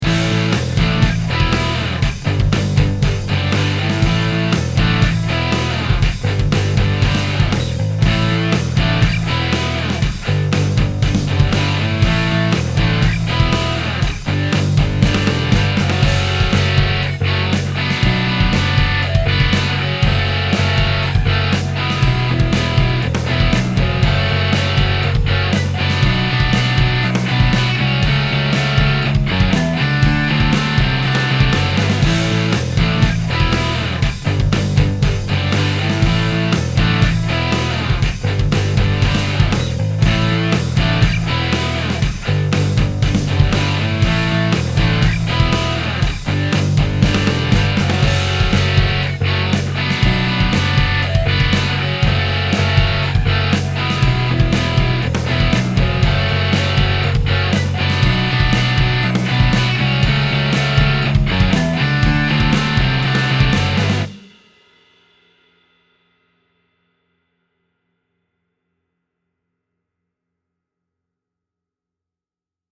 The result is a signal that appears to originate from the non-delayed side but fills the stereo field (since it is actually in both channels).
Single Haas:
Even though we can hear the delayed “ghost” in the right channel, we get the impression that the guitar is on the left side since the left channel is not delayed.